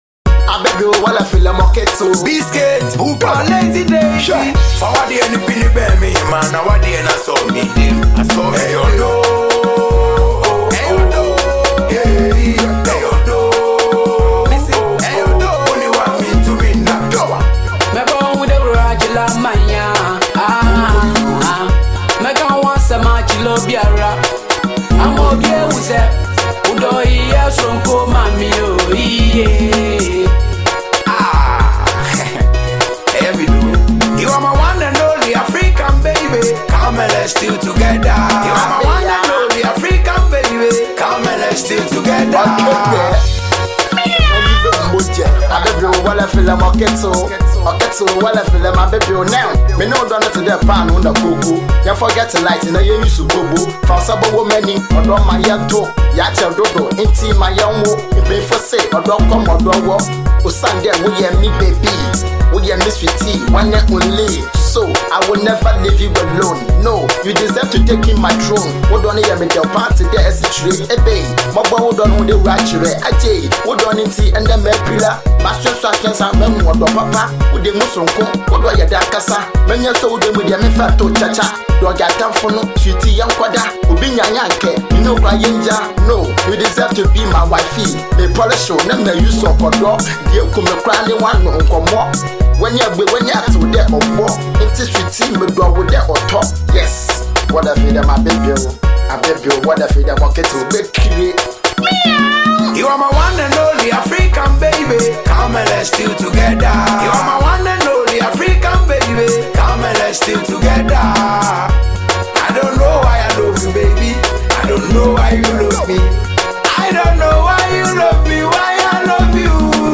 Ghana MusicMusic
Building Ghanaian rapper